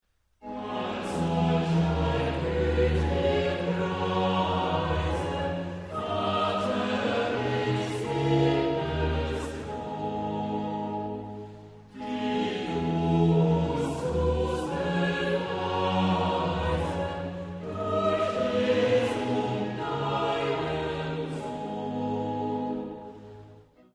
Maar er is Maar er is één belangrijke uitzondering: als op een opmaat aan het begin  (van een stuk of van een frase) een Ie trap wordt geplaatst, kan deze Ie trap op de eerste tel worden herhaald.